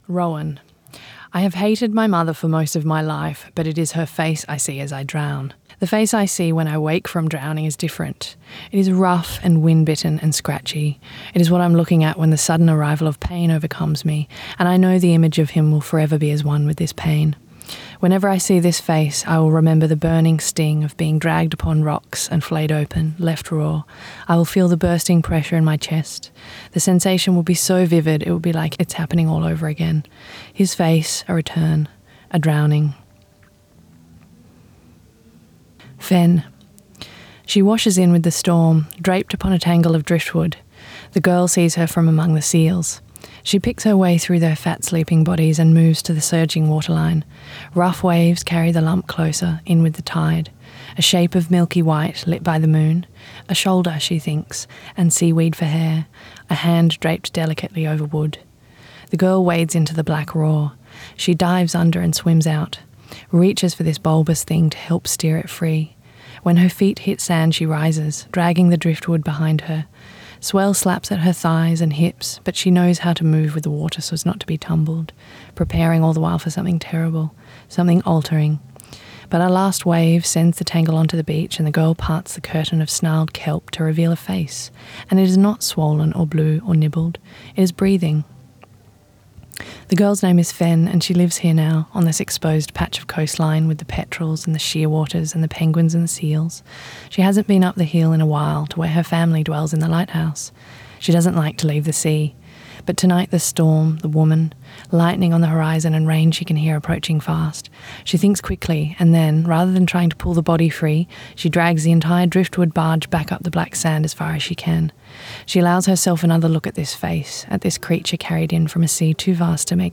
Recorded at Bellingen Readers and Writers Festival 2025